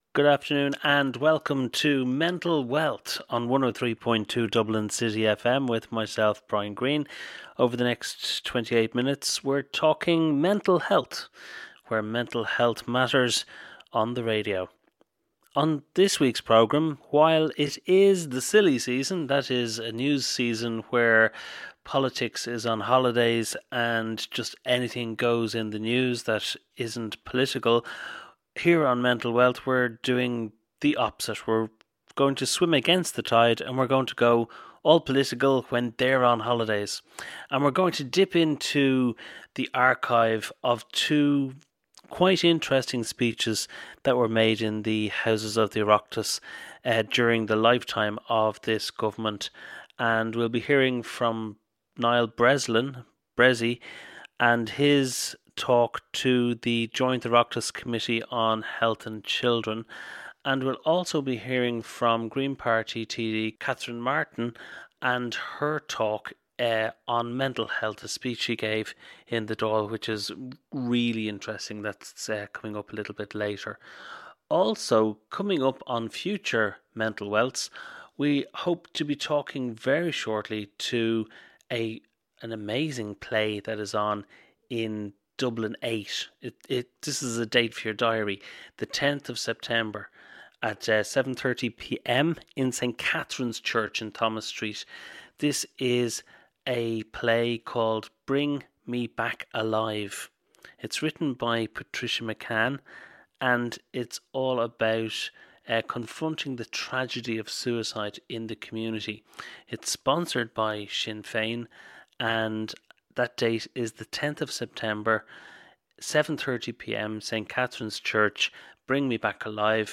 Niall Breslin speaking to the Joint Oireachtas committee on Health & Children January 2016 Catherine Martin TD speech on Mental Health April 2016